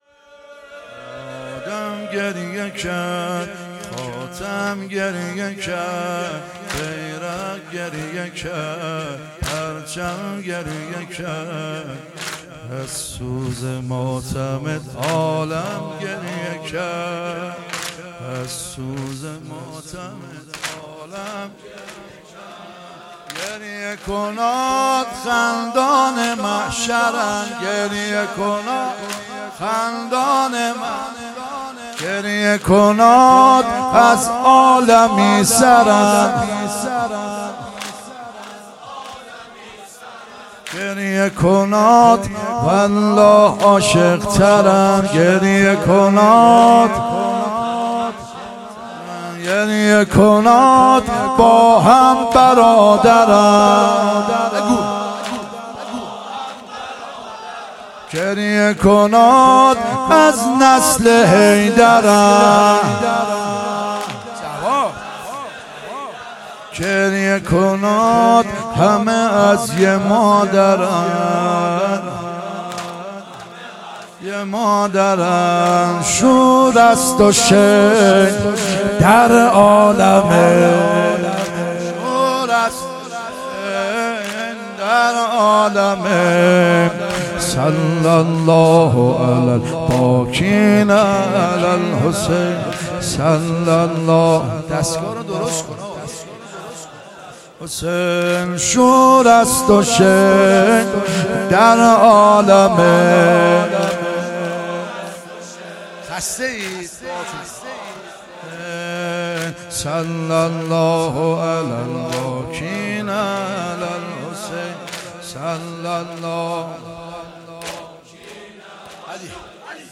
دهه اول صفر 1441 شب دوم